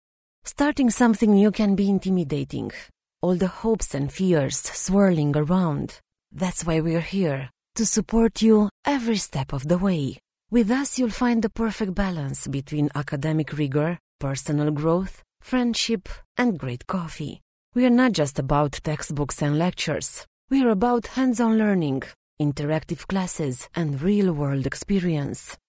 Female
Bright, Confident, Corporate, Friendly, Warm, Engaging, Natural
Neutral Romanian and English with Eastern European Accent
Microphone: Neumann TLM 107